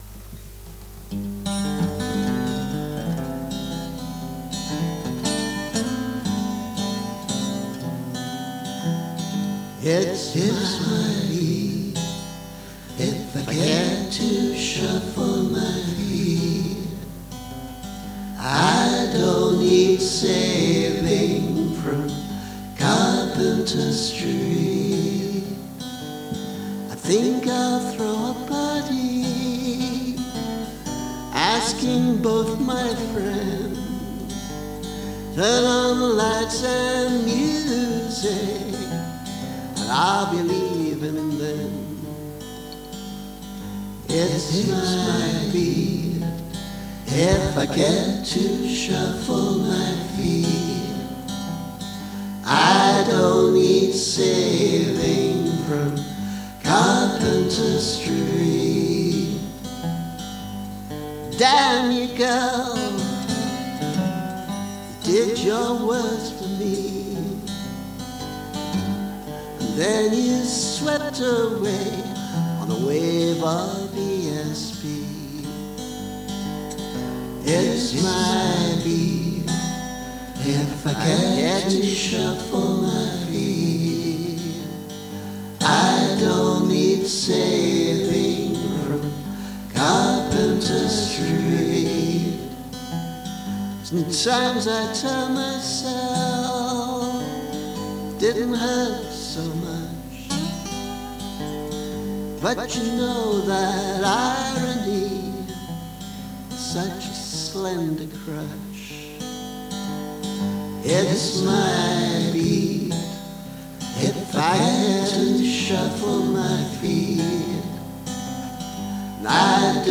Sometime in 2014 I suddenly remembered this song from the early 70s and put down a basic track, then got excited and put in some sketchy harmonies and a bit of lead. So it has some decent ideas but very hasty execution.
I hope to get back to this one Real Soon Now and clean up the harmonies, but in the meantime here’s a version lightly remastered to bring up the volume. Which you may think is a mistake. ;)